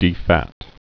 (dē-făt)